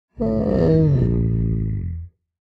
minecraft / sounds / mob / sniffer / idle6.ogg